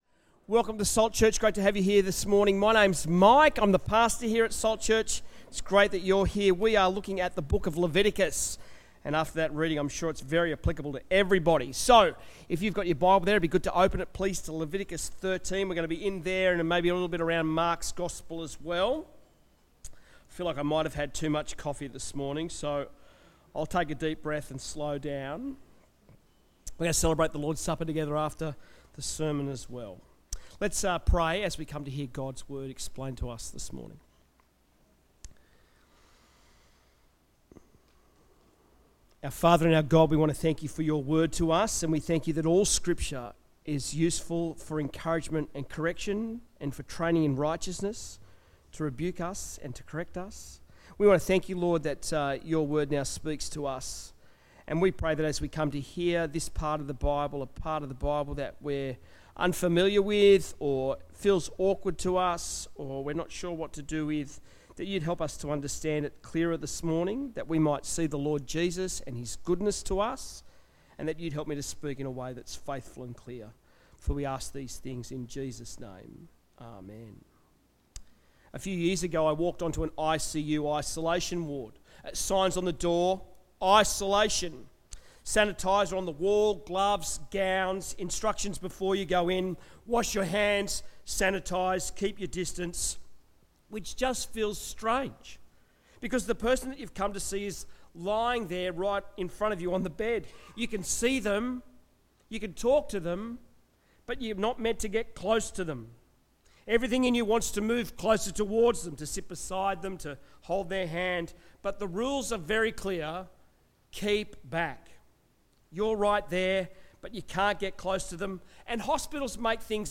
Sermons
Listen to our sermons from Sunday here